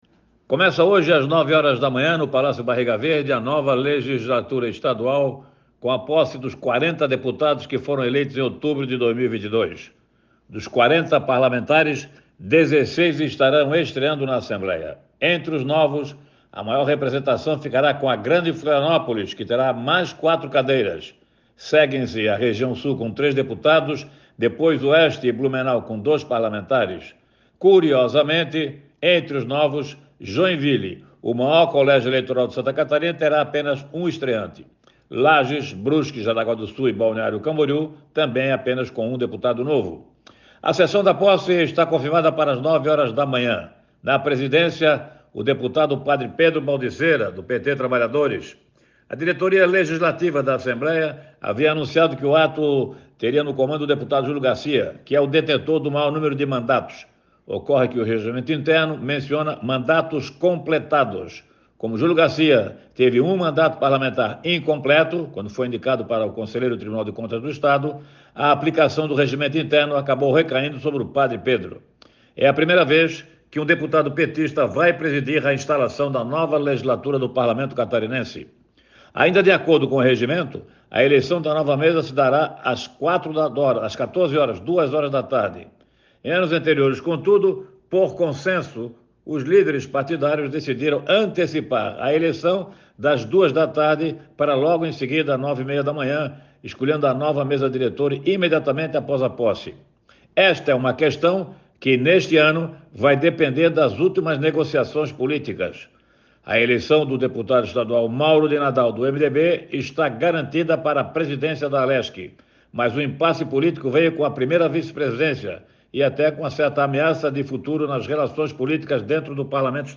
Confira na íntegra o comentário: